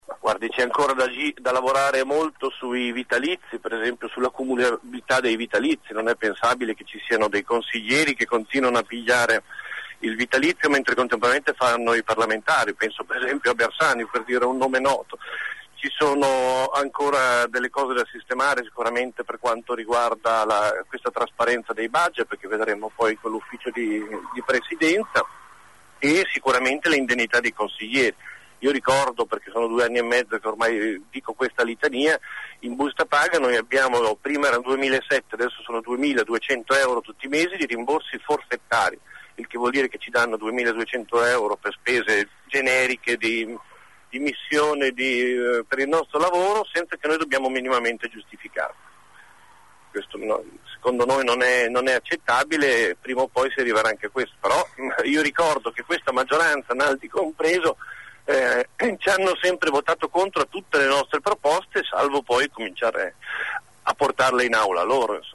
Ascolta il capogruppo del M5s in Regione Andrea De Franceschi